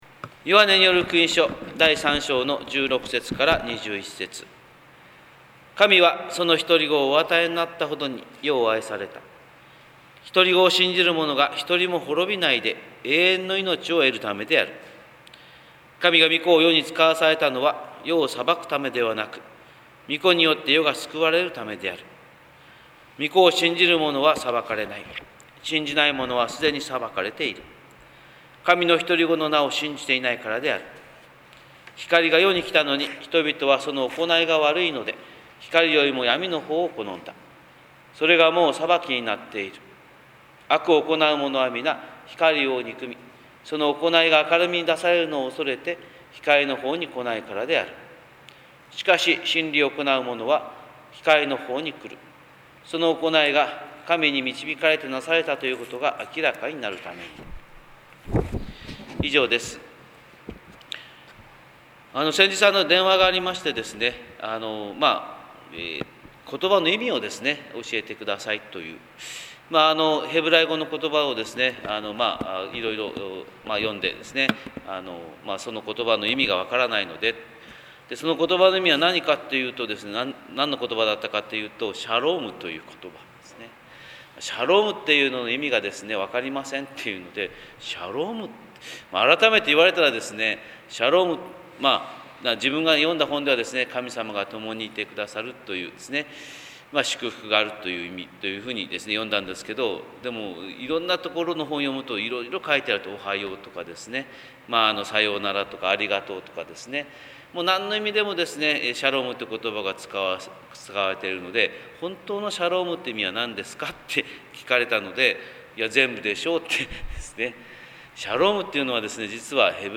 神様の色鉛筆（音声説教）
日本福音ルーテル教会（キリスト教ルター派）牧師の朝礼拝説教です！